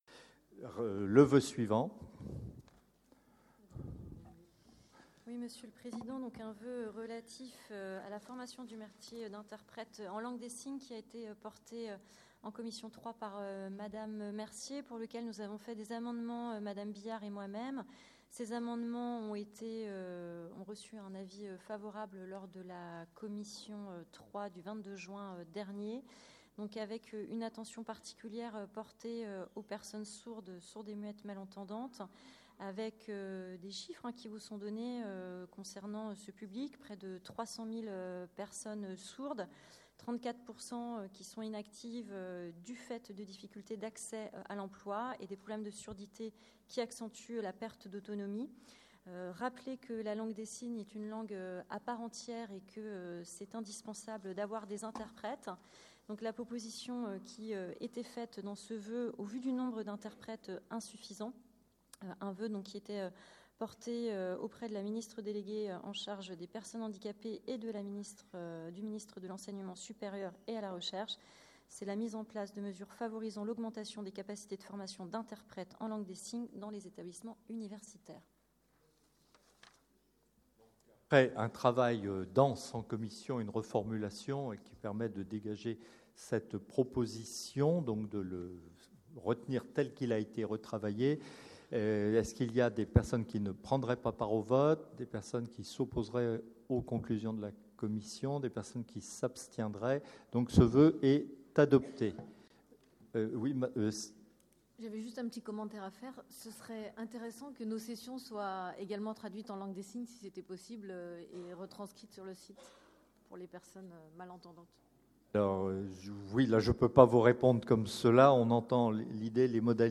• Assemblée départementale du 29/06/23